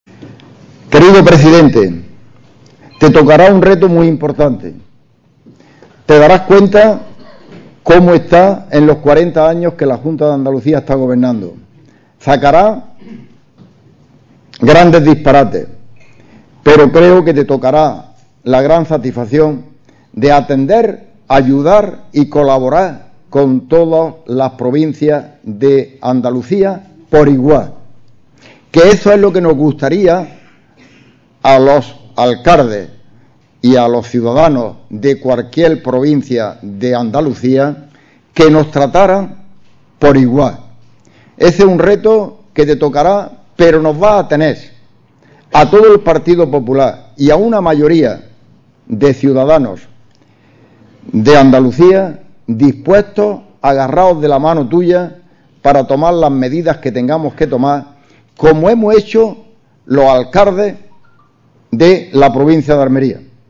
Escucha la noticia Asegura que mantiene la misma ilusión que cuando llegó a la Alcaldía de Roquetas hace 23 años Archivos Adjuntos Gabriel Amat: Descargar El presidente del PP de Almería, Gabriel Amat, ha comenzado su intervención ante el Consejo de Alcaldes señalando que el Partido Popular está preparado para que se celebren elecciones autonómicas desde ya, y se ha mostrado convencido de que el próximo presidente que saldrá de las urnas para nuestra comunidad será Juanma Moreno.